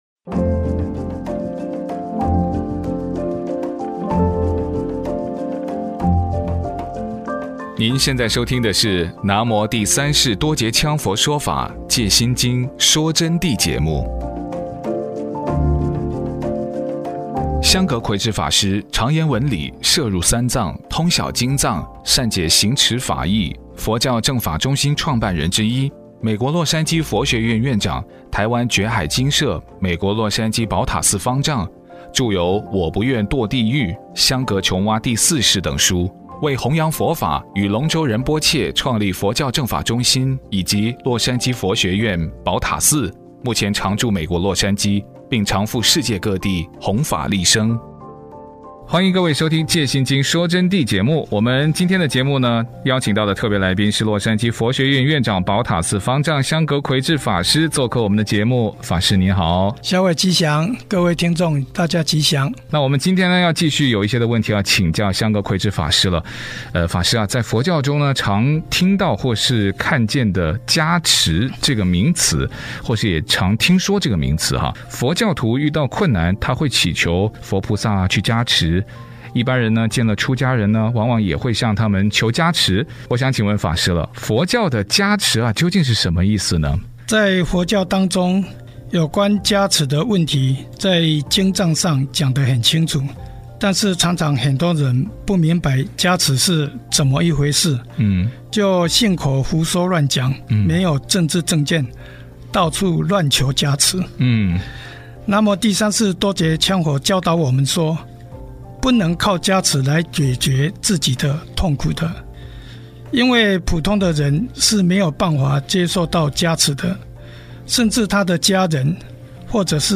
佛弟子访谈（三十）佛教的加持是什么意思？加持从何而来？如何获得加持？